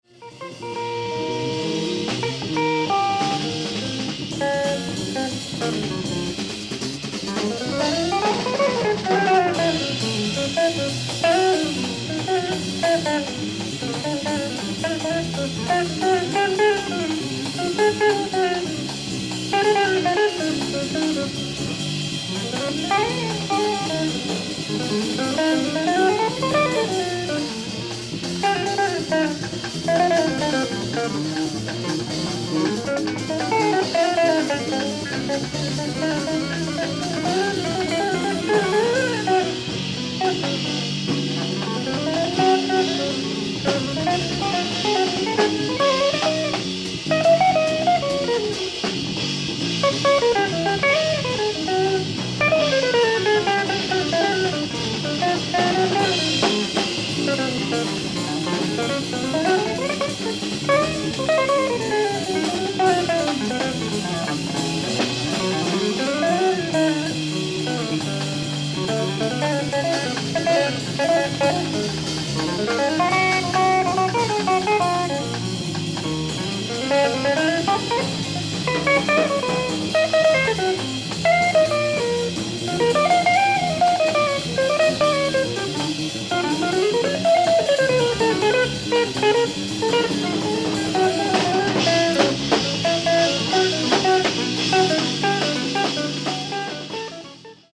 ネット音源とは異なるノイズレスのレストア盤！！
※試聴用に実際より音質を落としています。